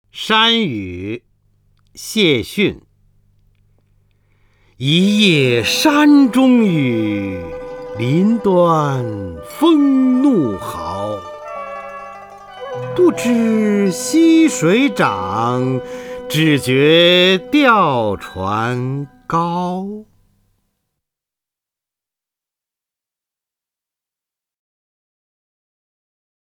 方明朗诵：《山雨》(（元）偰逊) （元）偰逊 名家朗诵欣赏方明 语文PLUS
（元）偰逊 文选 （元）偰逊： 方明朗诵：《山雨》(（元）偰逊) / 名家朗诵欣赏 方明